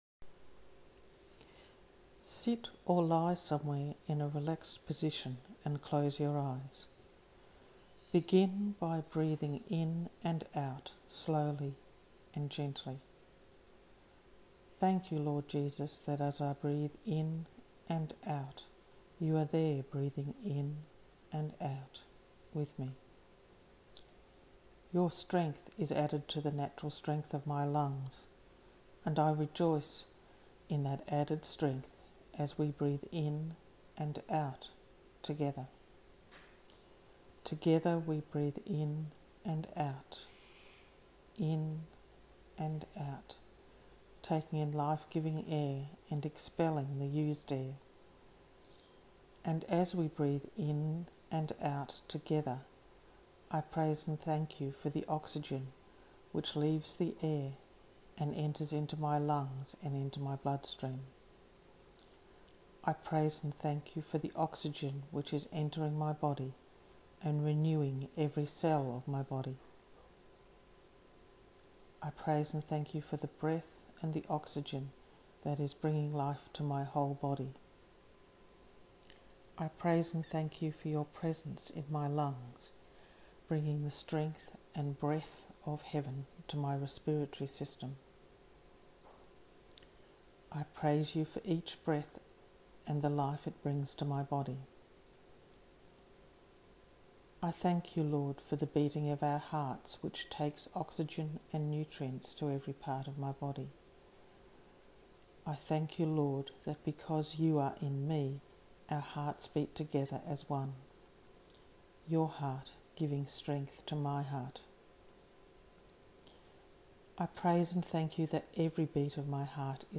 A spoken version of this text is available in this audio file so you can use it as a meditation guide: